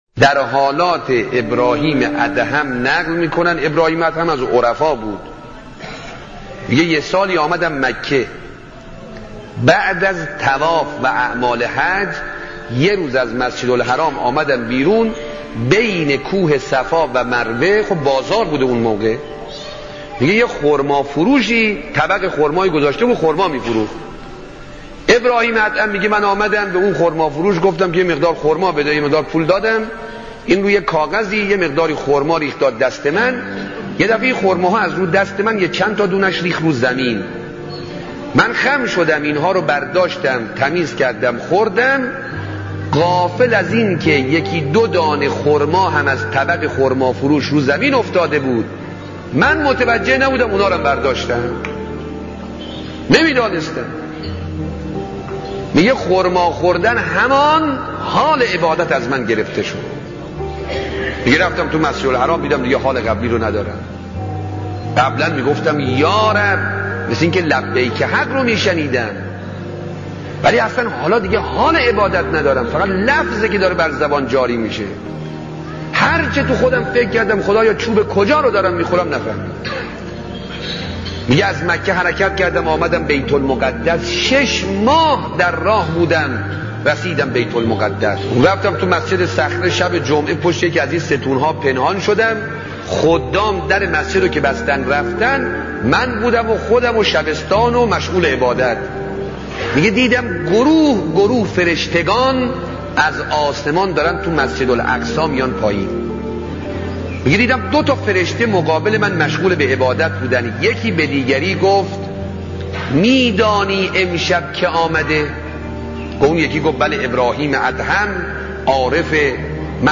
سخنرانی اموزنده, سخنرانی کوتاه